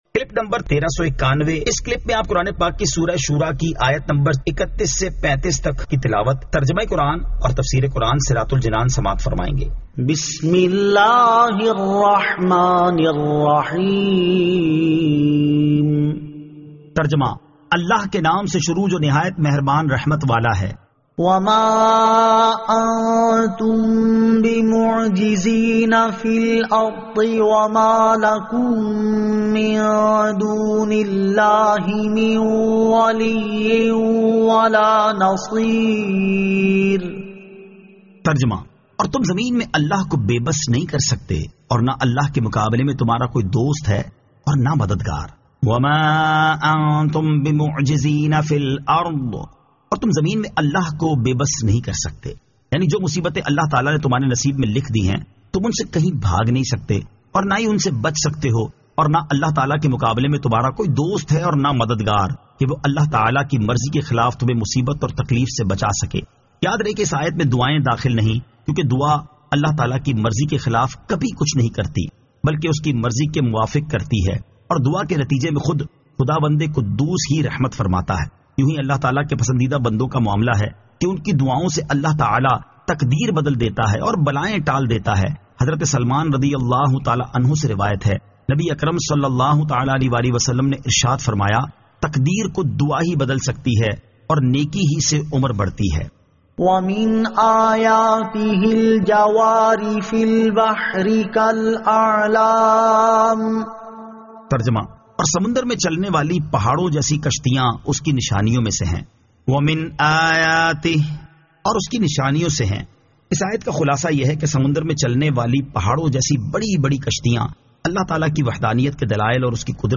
Surah Ash-Shuraa 31 To 35 Tilawat , Tarjama , Tafseer